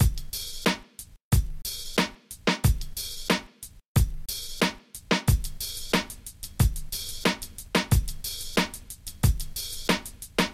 描述：一小包史诗般的弦乐和低音。
Tag: 140 bpm Cinematic Loops Strings Loops 1.08 MB wav Key : C